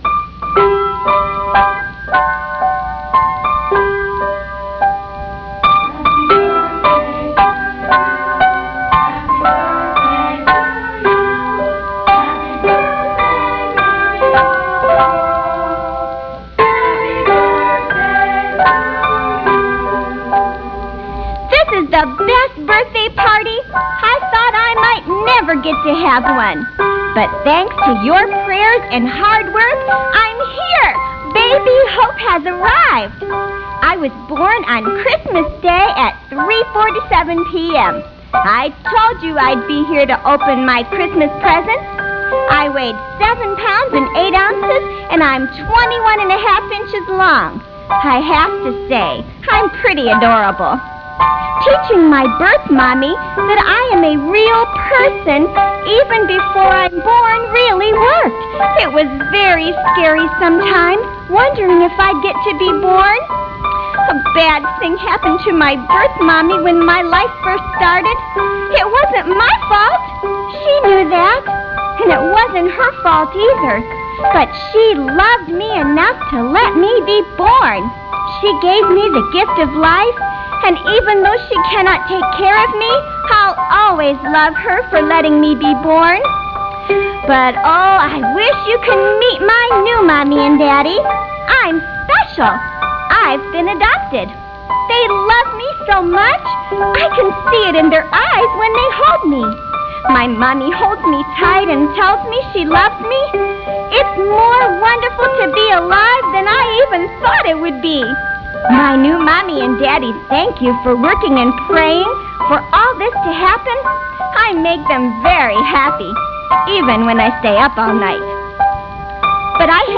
Baby Hope was originally created as commercials for a pro-life rally aired on my husband's radio program. I researched the development of a pre-born baby month by month, then became the"voice" of that baby.